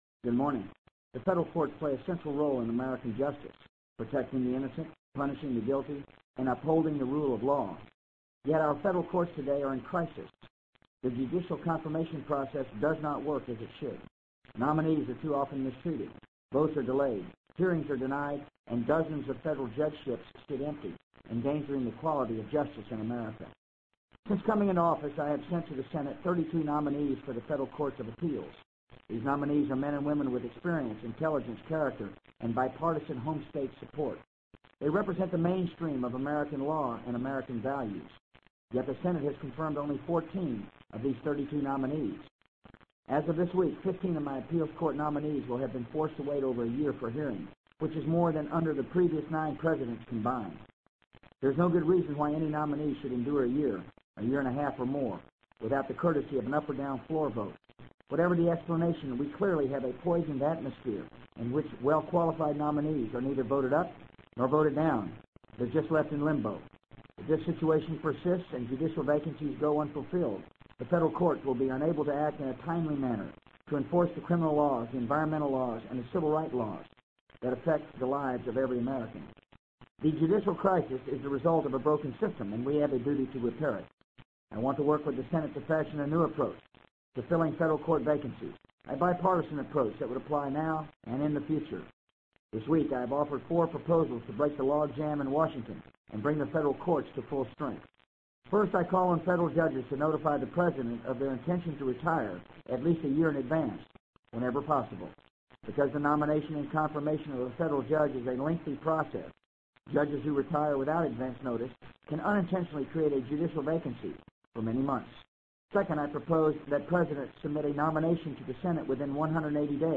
【美国总统George W. Bush电台演讲】2002-11-02 听力文件下载—在线英语听力室